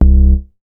MoogLoFi 002.WAV